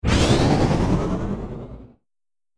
دانلود آهنگ کشتی 10 از افکت صوتی حمل و نقل
جلوه های صوتی
دانلود صدای کشتی 10 از ساعد نیوز با لینک مستقیم و کیفیت بالا